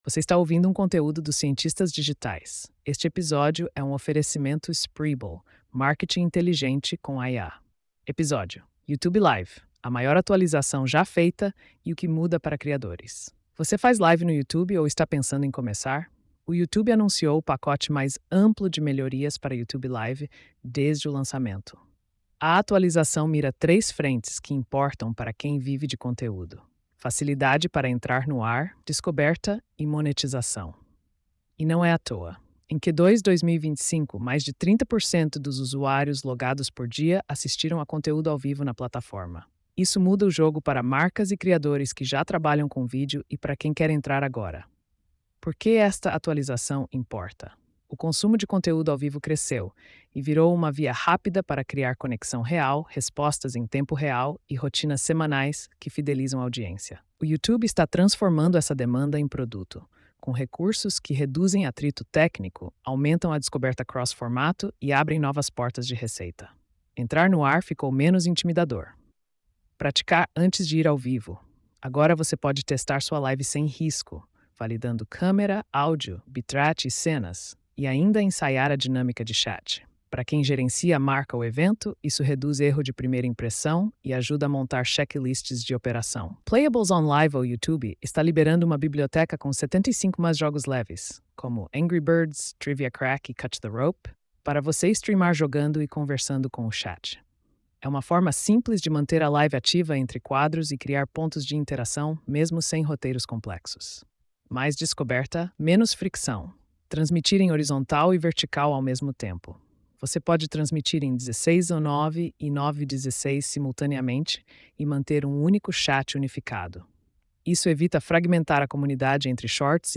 post-4426-tts.mp3